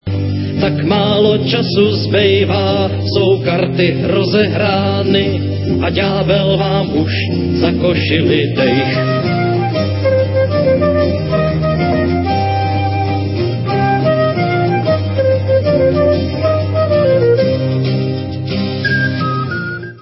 Renesančně laděnými autorskými písněmi